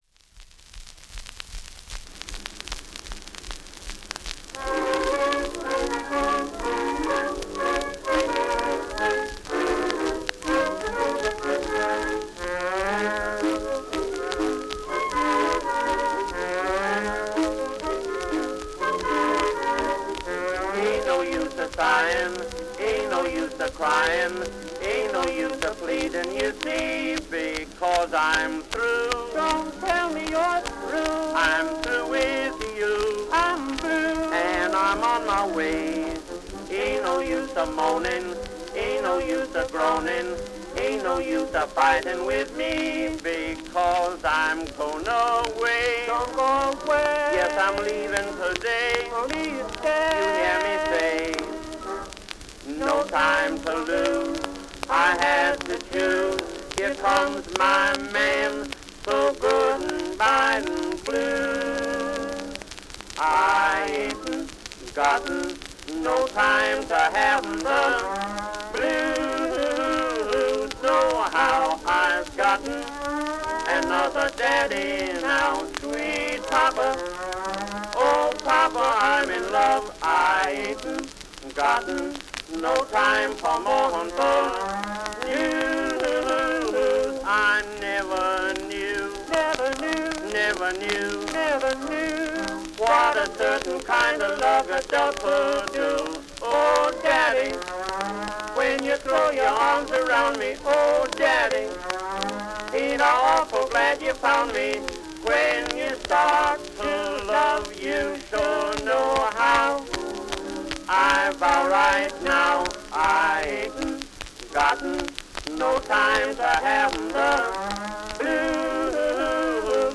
Genre: Early Jazz / Vaudeville / 1920s Popular Music